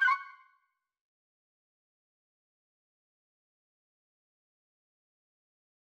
error_style_4_002.wav